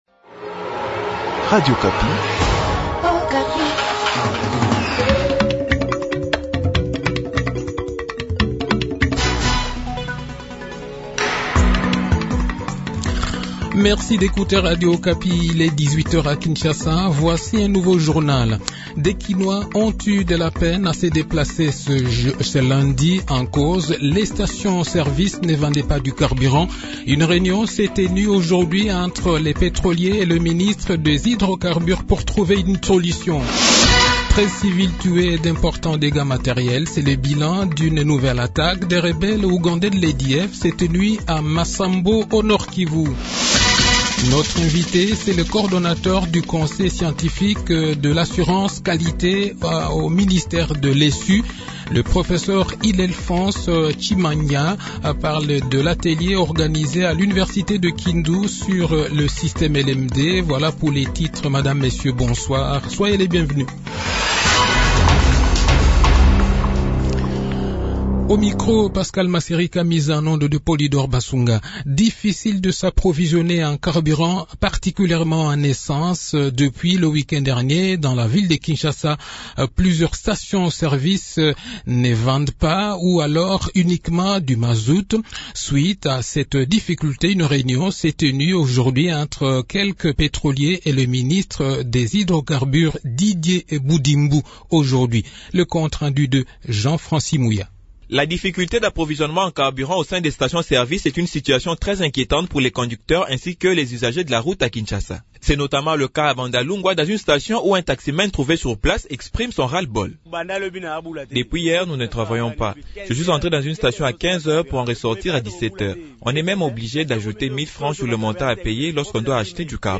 Le journal de 18 h, 4 Avril 2022